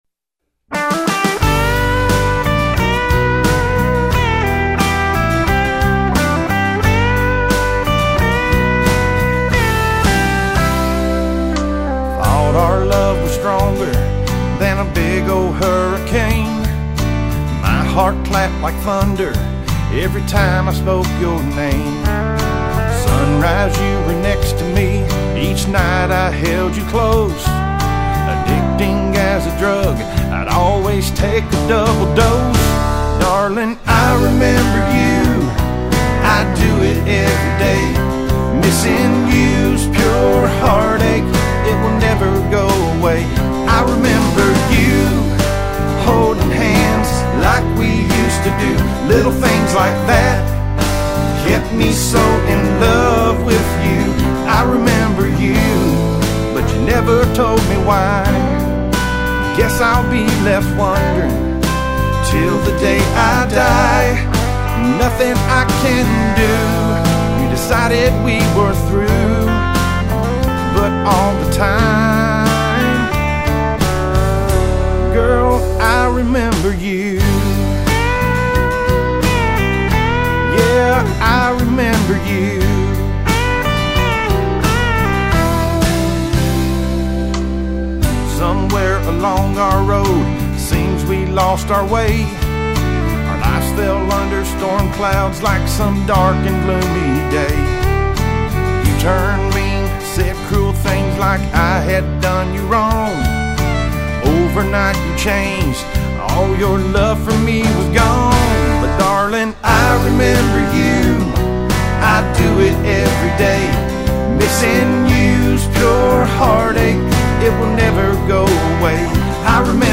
Complete Demo Song, with lyrics and music